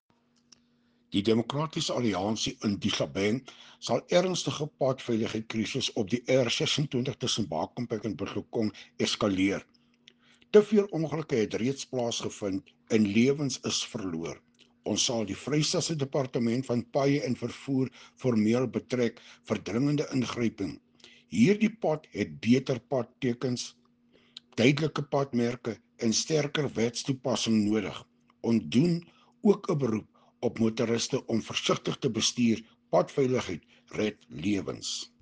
Afrikaans soundbites by Cllr Hilton Maasdorp and